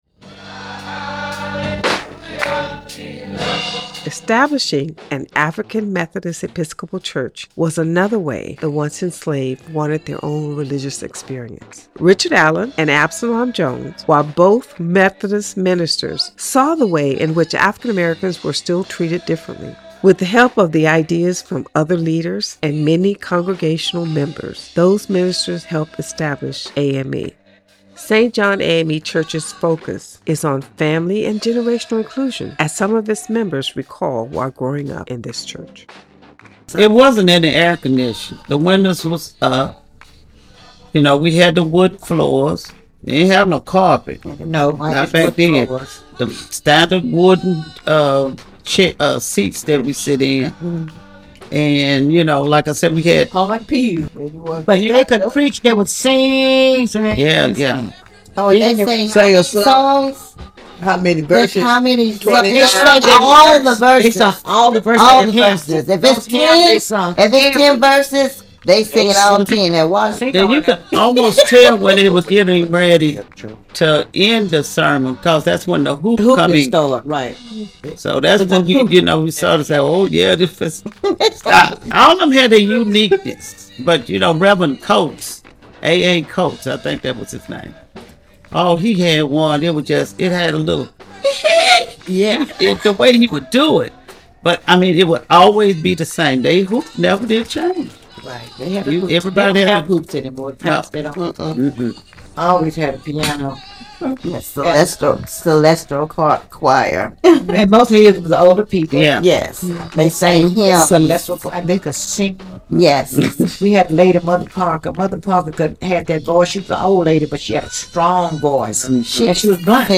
You will go on a tour that includes sounds and stories of the area.